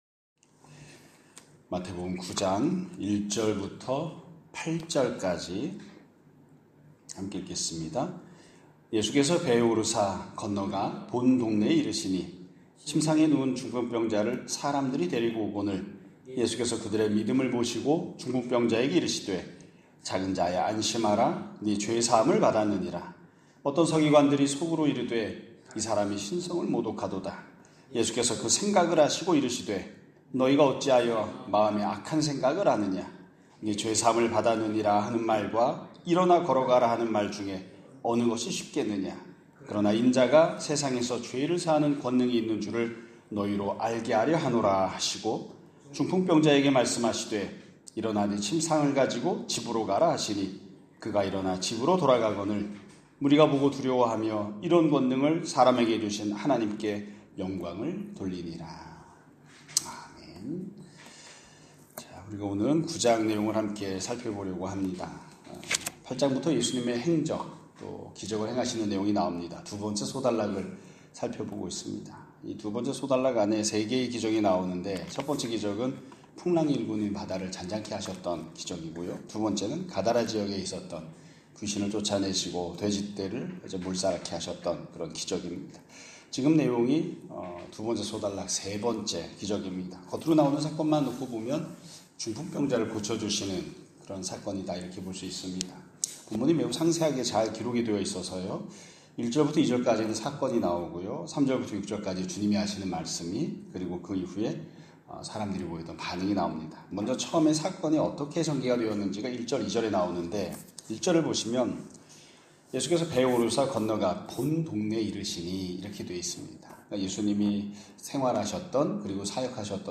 2025년 7월 18일(금요일) <아침예배> 설교입니다.